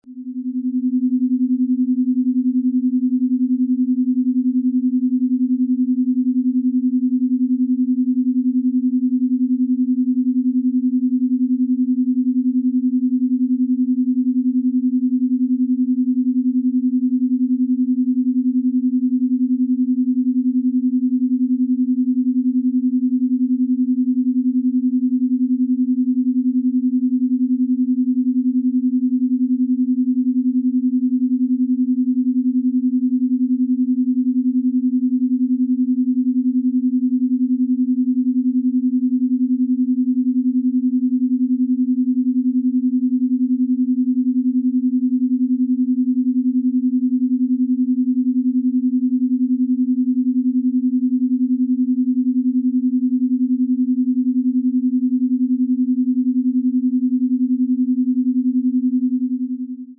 The audio frequency samples below were recorded with the app.
248 Hz & 258.5 Hz : 10.5 Hz beat frequency (Alpha)
Binaural beat.